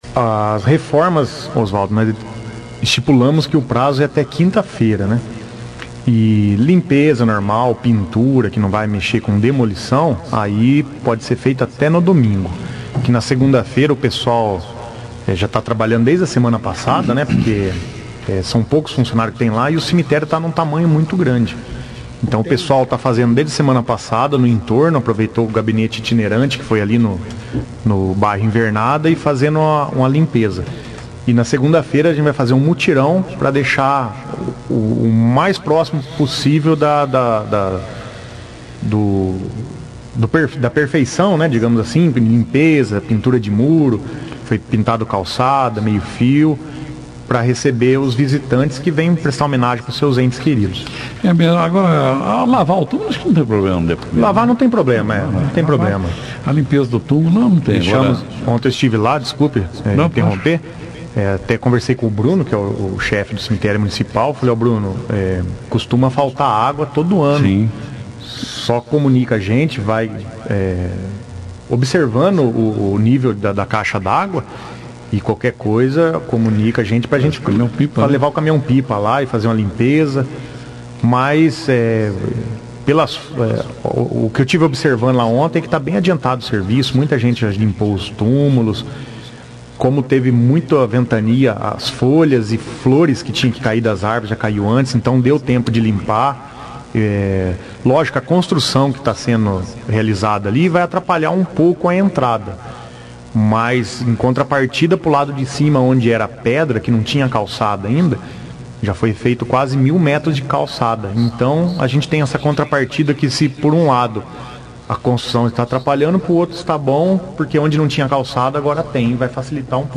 O prefeito de Bandeirantes, Jaelson Matta e o Secretário de Administração, Cleber Batista, participaram da 2ª edição do jornal Operação Cidade desta terça-feira, 26/10, falando sobre o trabalho realizado no cemitério municipal visando à passagem do Dia de Finados, na próxima terça-feira, 02/11.